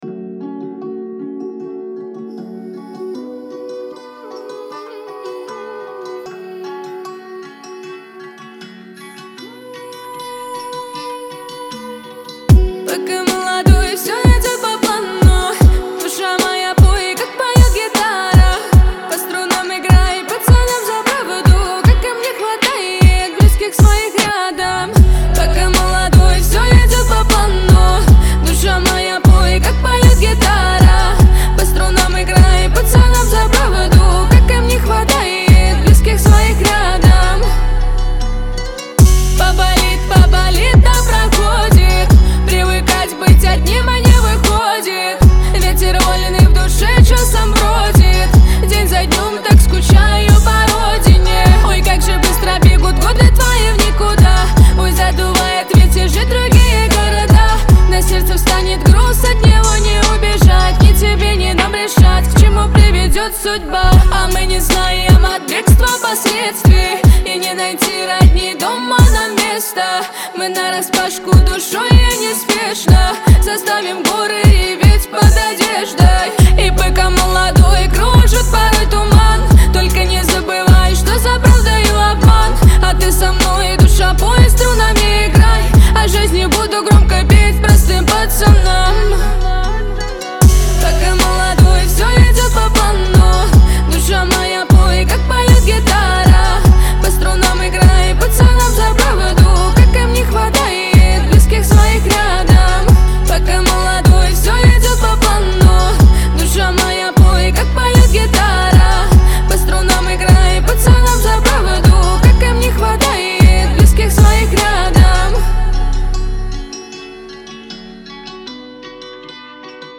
pop , Шансон
Лирика
грусть